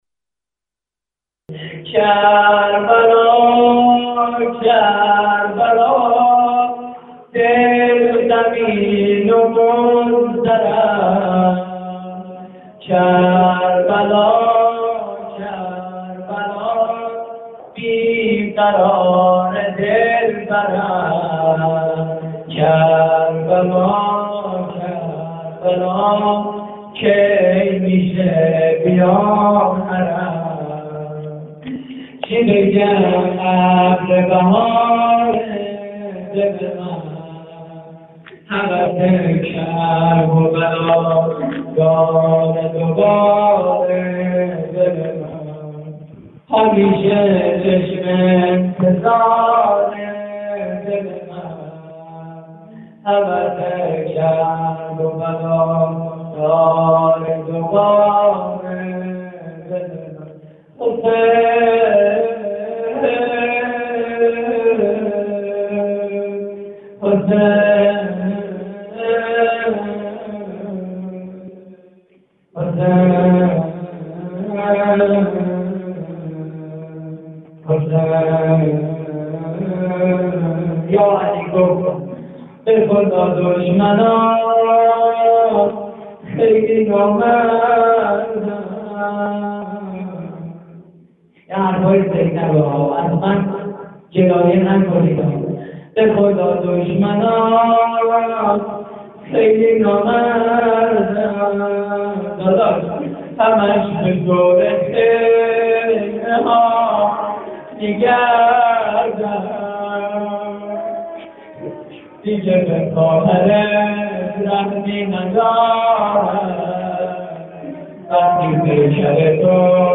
مراسم هفتگی (پیشواز محرم) / هیئت کانون دانش آموزی حضرت سیدالکریم (ع)؛ شهرری - 11 آذر 89
صوت مراسم:
روضه پایانی: به خدا دشمنات خیلی نامردن؛ پخش آنلاین |